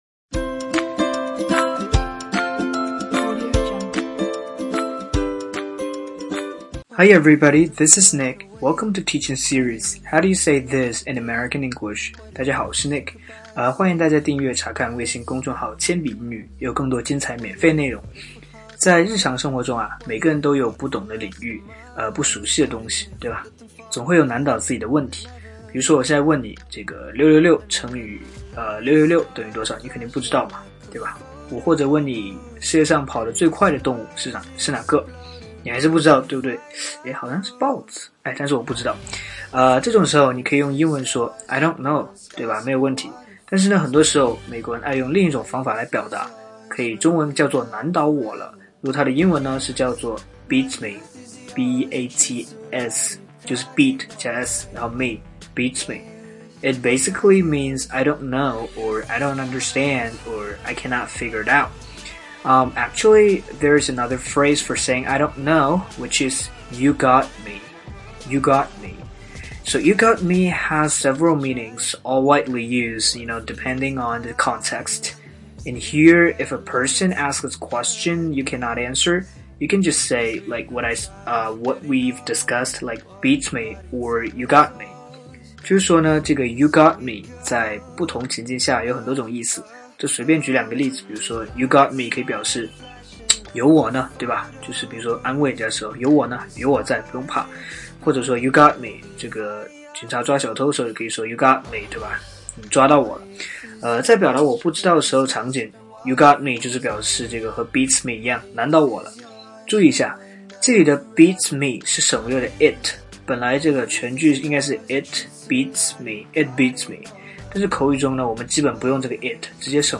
在线英语听力室全网最酷美语怎么说:第21期 难倒我了的听力文件下载, 《全网最酷美语怎么说》栏目是一档中外教日播教学节目，致力于帮大家解决“就在嘴边却出不出口”的难题，摆脱中式英语，学习最IN最地道的表达。